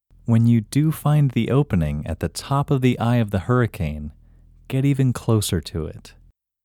IN – Second Way – English Male 10